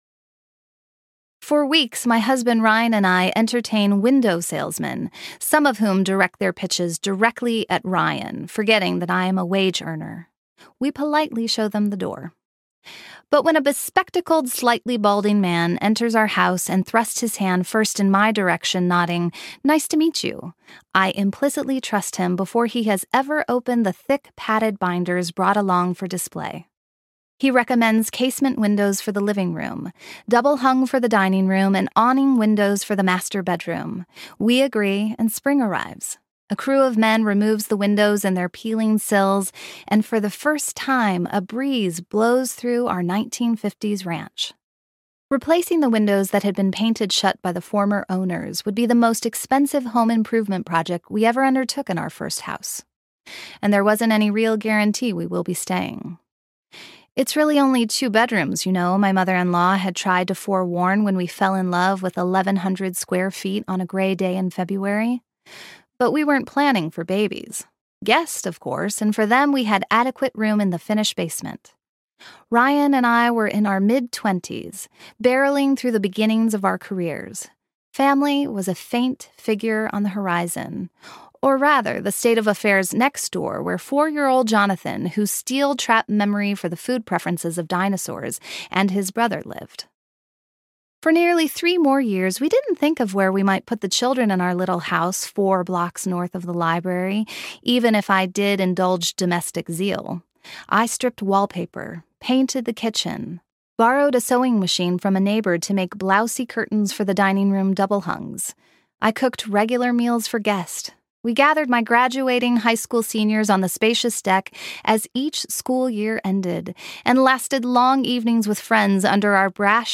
Keeping Place Audiobook